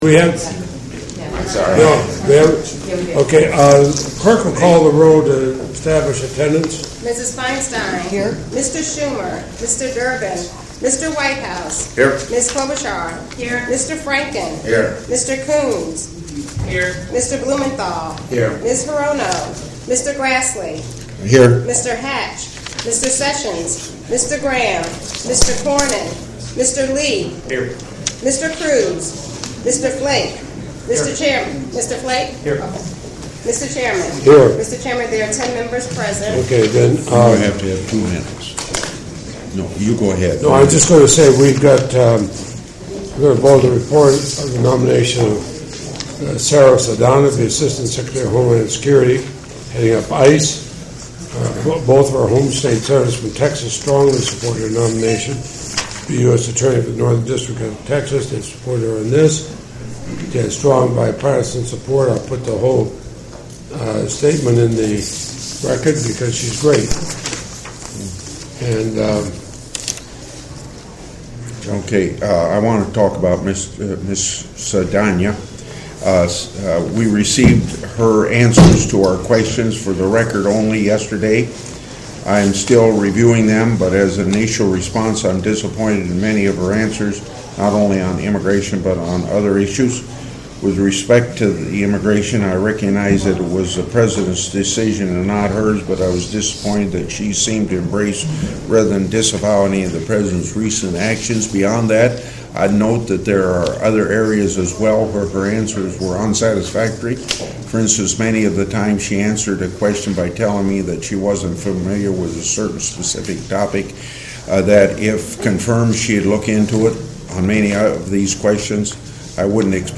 Type: Executive Business Meeting
Location: President's Room S-216 of the Capitol